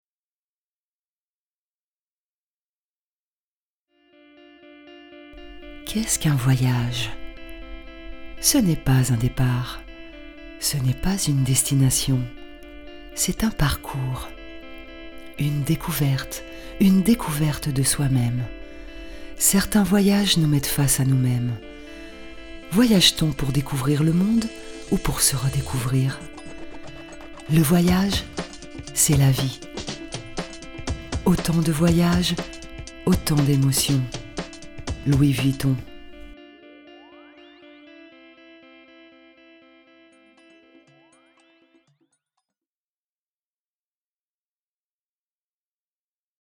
comédienne voix off / doublage
Kein Dialekt
Sprechprobe: eLearning (Muttersprache):